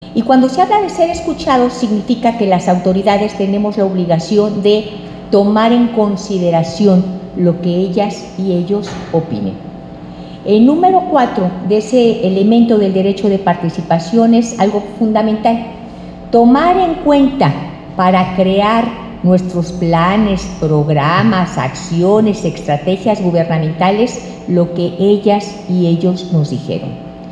Así lo subraya Thais Loera Ochoa, Secretaria Ejecutiva del Sistema Estatal de Protección Integral de Niñas, Niños y Adolescentes.